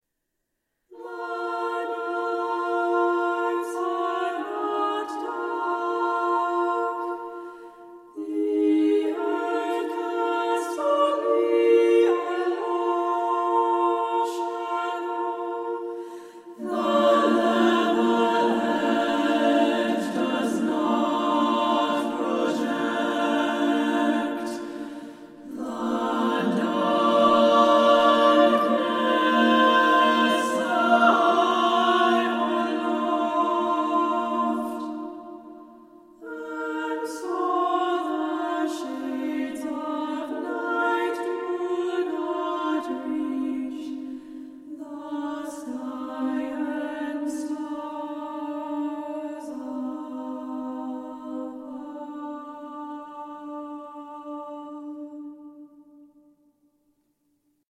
treble voice choir
Recorded August 8, 2021, Mechanics Hall, Worcester, MA